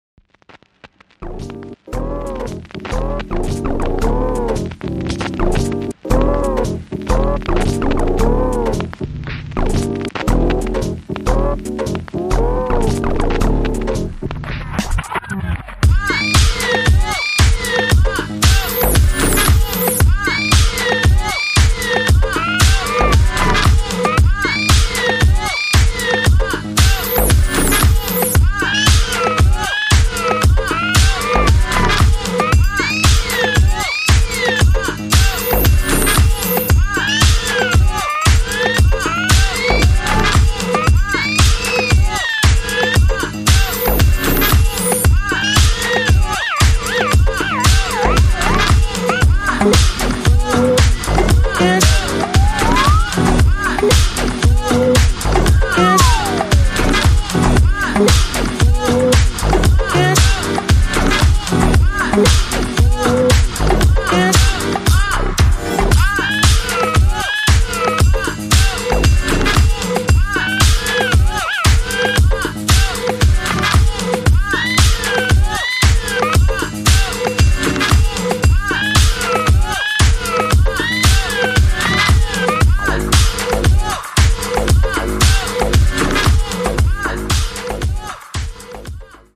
Disco / Balearic